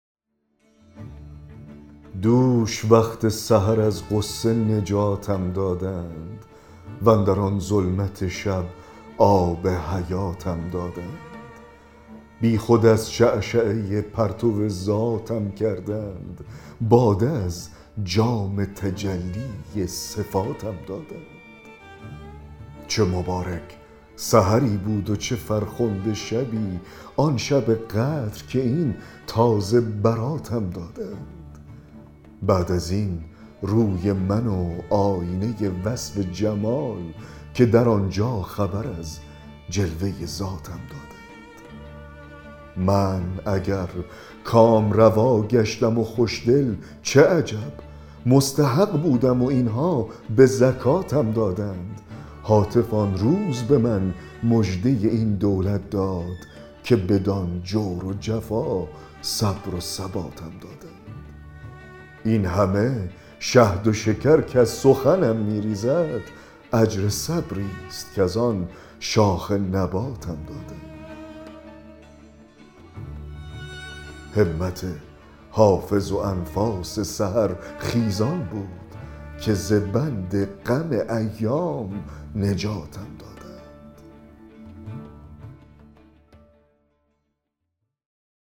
دکلمه غزل 183 حافظ
دکلمه-غزل-183-حافظ-دوش-دیدم-که-ملایک-در-میخانه-زدند.mp3